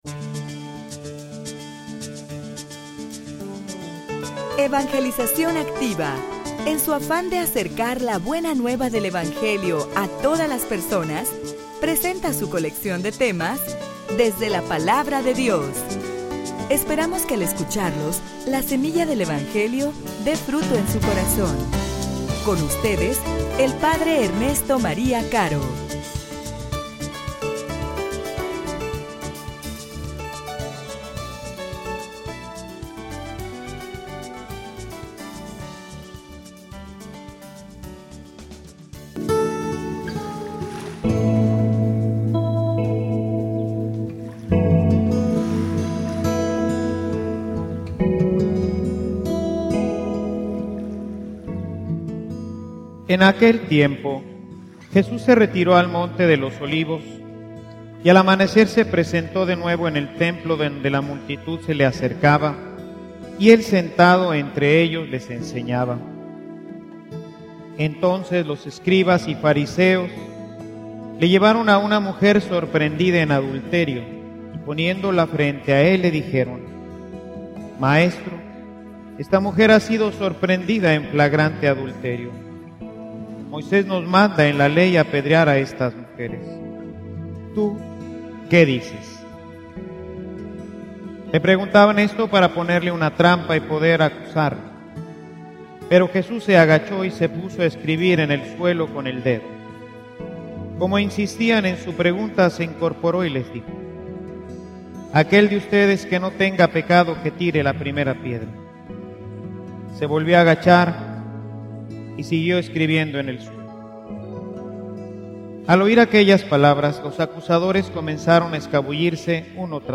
homilia_No_quiero_tu_muerte_sino_tu_conversion.mp3